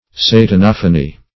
Search Result for " satanophany" : The Collaborative International Dictionary of English v.0.48: Satanophany \Sa`tan*oph"a*ny\, n. [Satan + Gr.